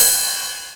VEC3 Cymbals Ride 18.wav